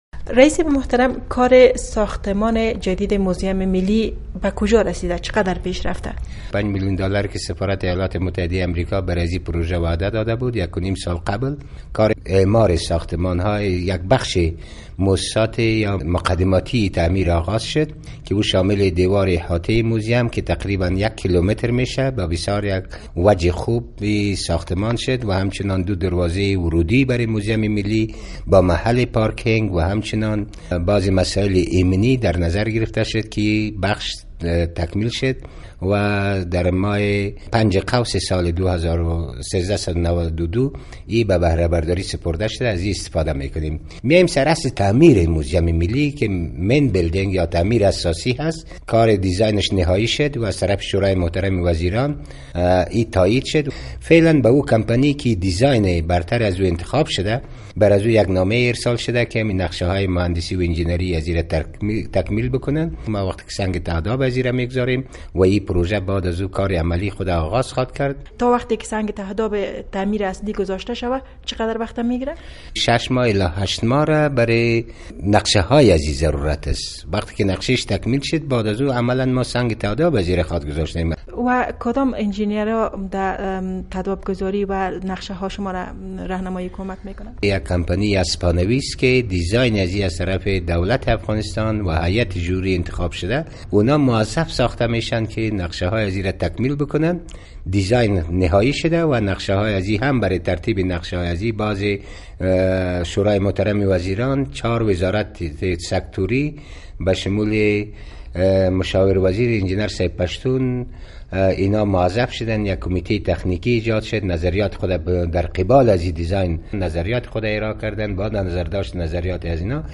مصاحبه ها
عمرا خان مسعودی، رئیس موزیم ملی افغانستان در گفتگو با رادیو آشنا صدای امریکا که بر اساس پیش بینی ها کار ساختمانی تعمیر موزیم جدید ۳۵ الی ۴۰ میلیون دالر هزینه بر خواهد داشت.